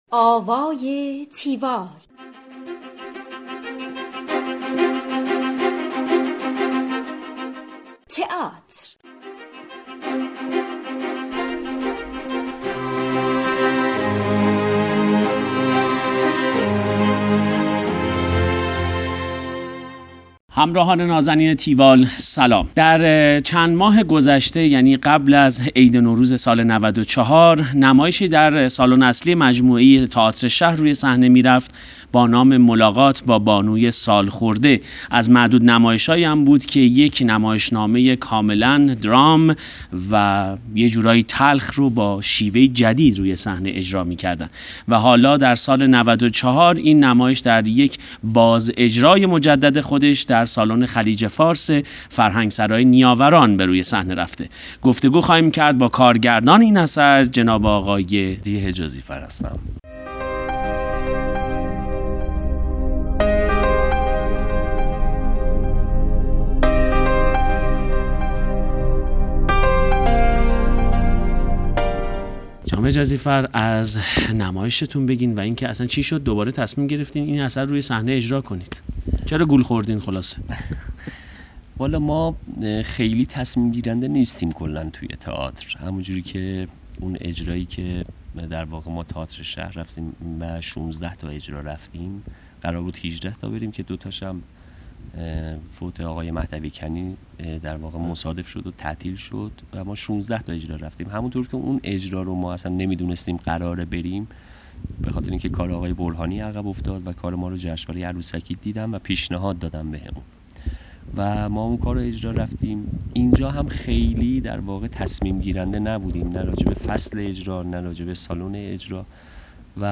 گفتگوی تیوال با هادی حجازی فر
tiwall-interview-hadihejazifar.mp3